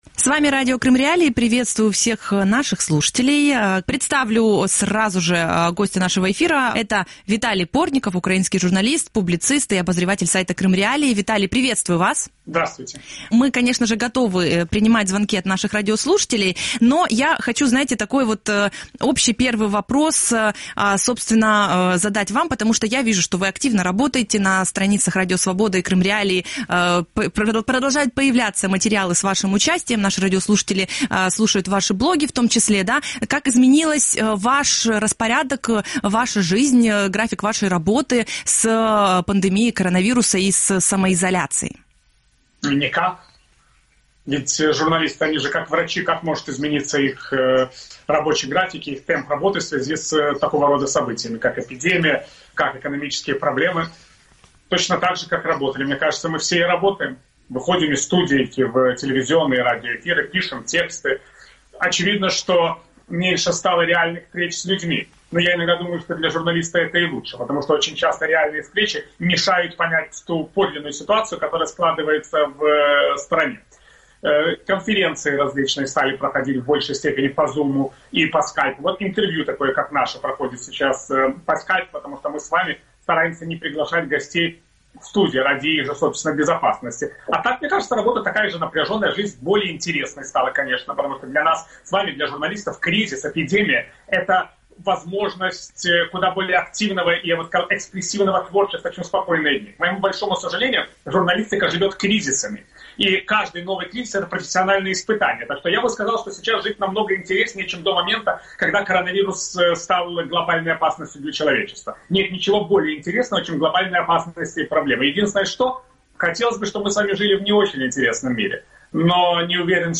говорит с украинским журналистом, обозревателем Крым.Реалии Виталием Портниковым